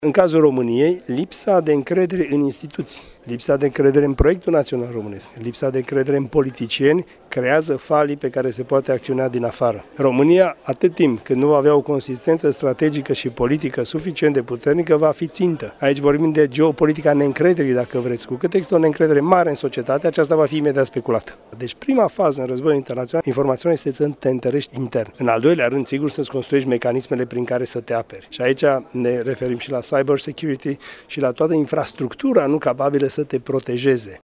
La Iași, s-a desfășurat, astăzi, conferința cu tema „Provocări de securitate în Sud-Estul Europei”, organizată de universităţile Alexandru Ioan Cuza şi Tehnică Gheorghe Asachi şi de Organizația New Strategy Center.